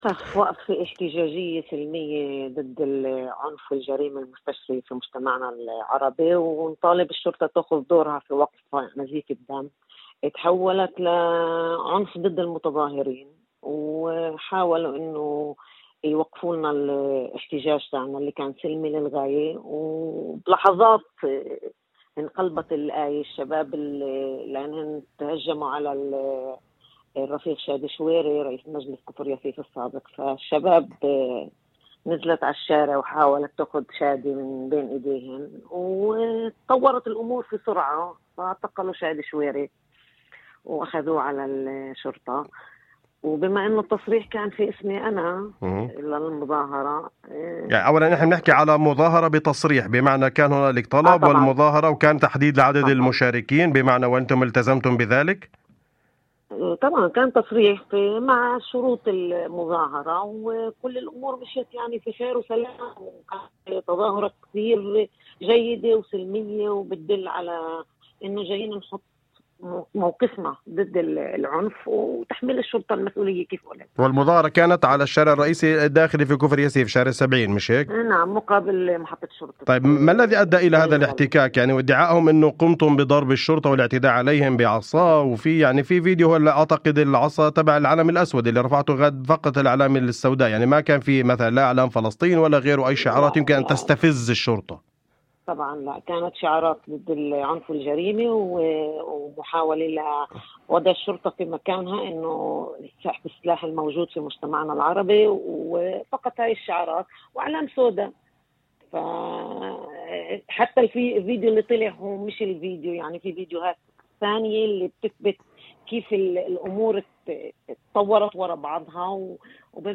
وأضافت مداخلة هاتفية لبرنامج "أول خبر"، على إذاعة الشمس: